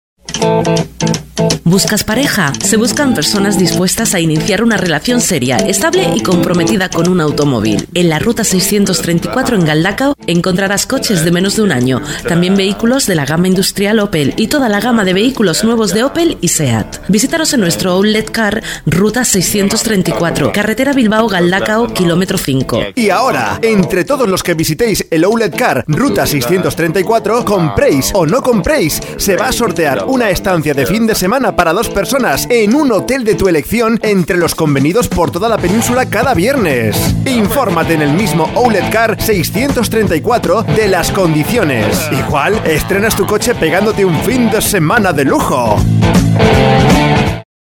CUÑAS PUBLICITARIAS